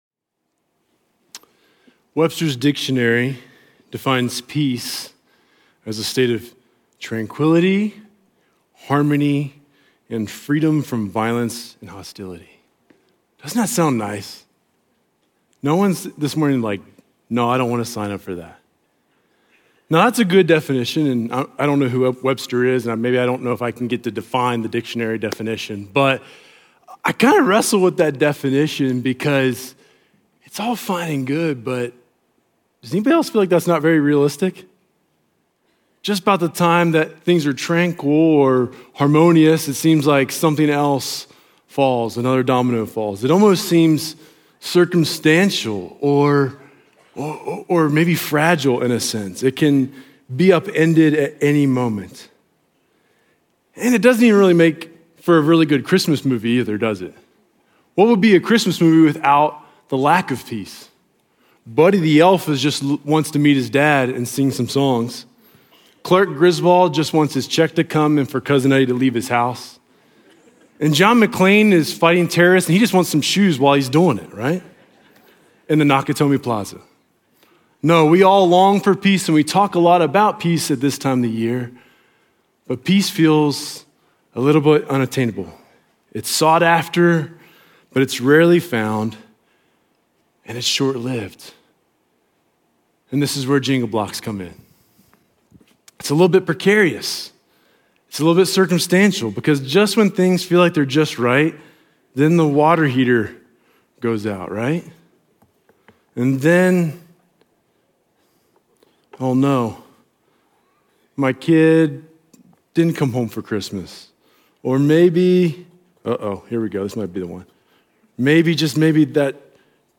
Sunday worship gathering sermon audio from Journey Church in Bozeman, Montana.